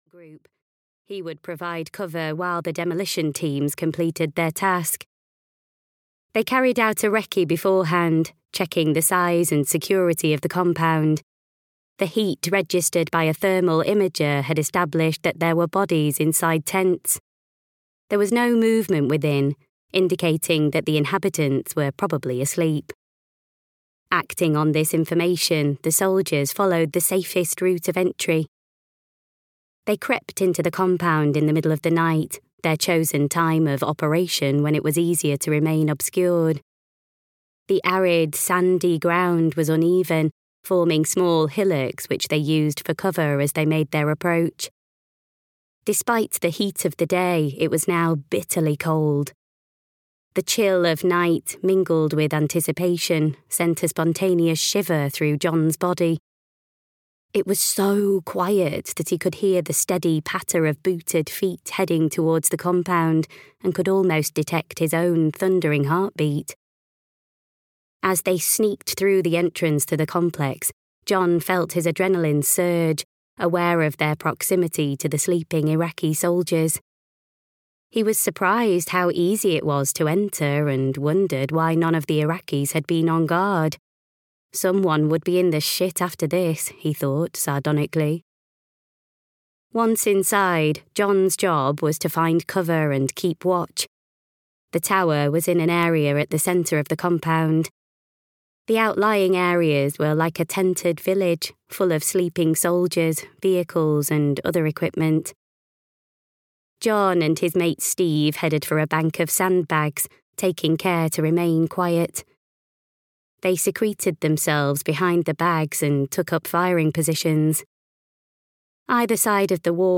Danger By Association (EN) audiokniha
Ukázka z knihy